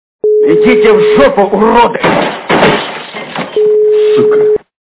- Из фильмов и телепередач
При прослушивании Из к.ф. Жмурки - Идите в ж..., уроды! качество понижено и присутствуют гудки.